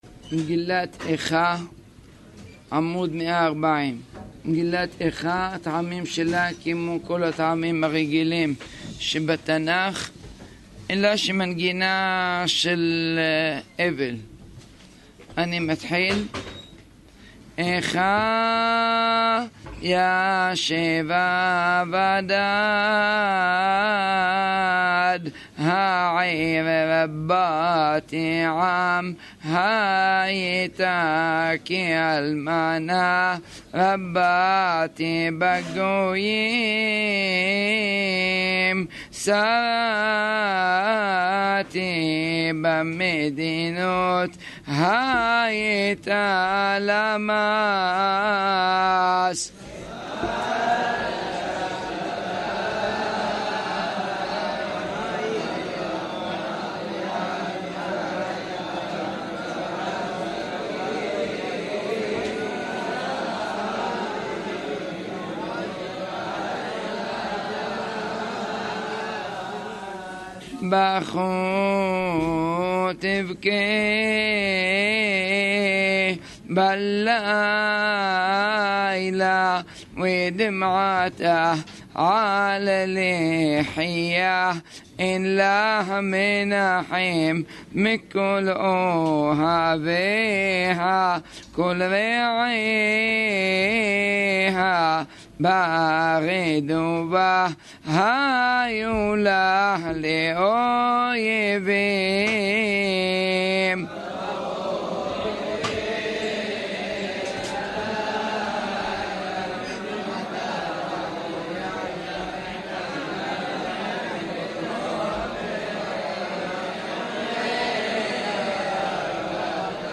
קריאת מגילת איכה
פתיחה קטנה על המגילה ואחרי כן קריאת המגילה פרק א וקצת מפרק ב' עם הבחורים דרך נפלאה ללימוד הטעמים
טעמי המקרא